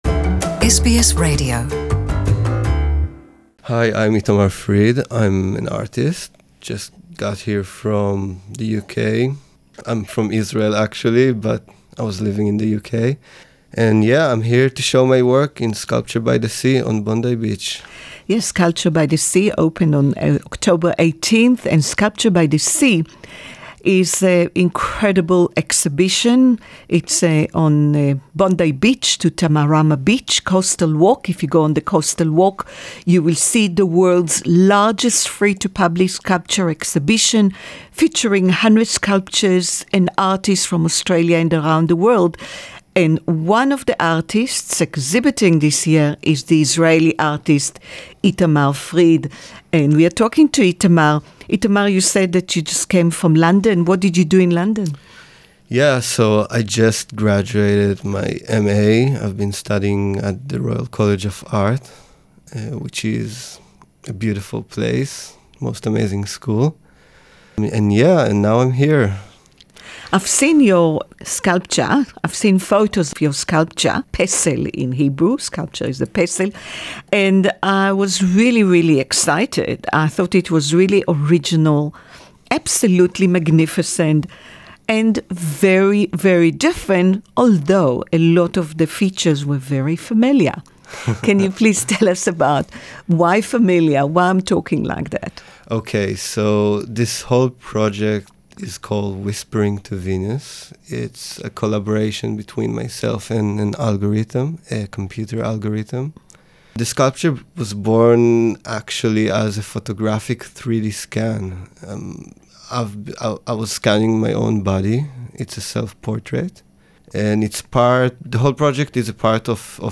exhibiting in "Sculpture by the Sea" Interview in English